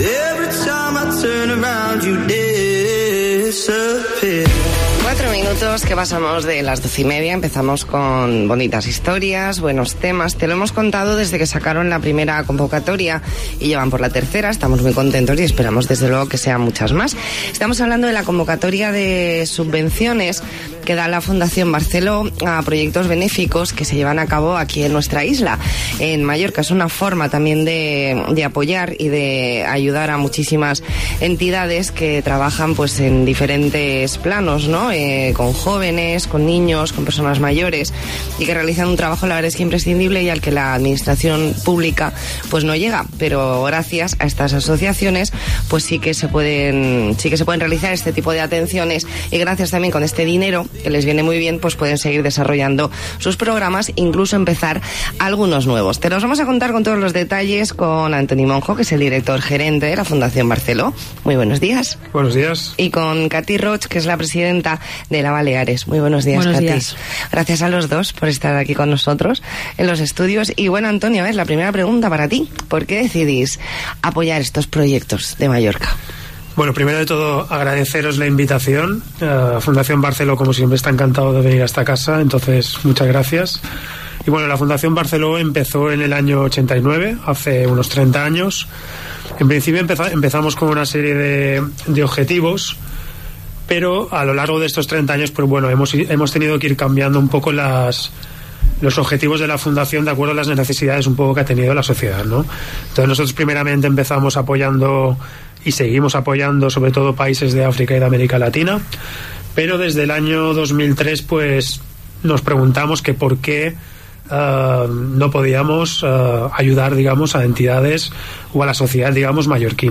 Entrevista en La Mañana en COPE Más Mallorca, jueves 27 de febrero de 2020. La Fundación ya ha hecho pública la resolución de su III convocatoria de subvenciones para la realización de proyectos benéficos en Mallorca.